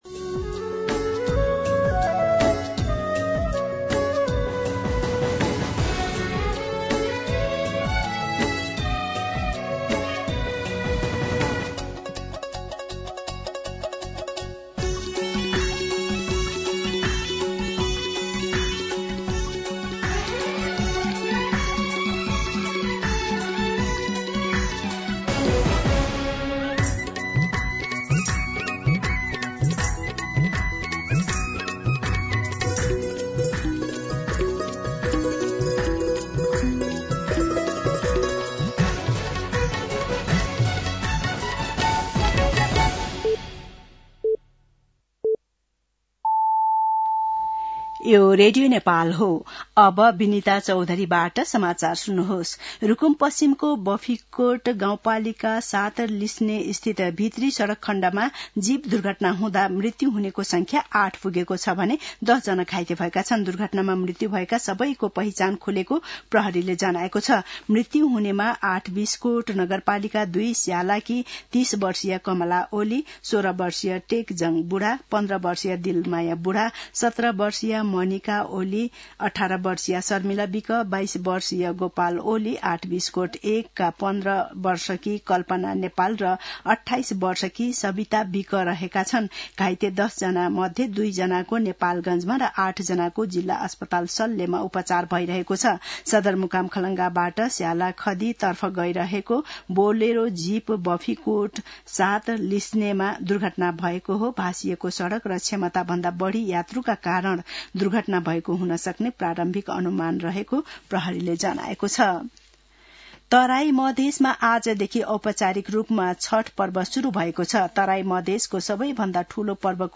मध्यान्ह १२ बजेको नेपाली समाचार : ८ कार्तिक , २०८२
12-pm-Nepali-News-9.mp3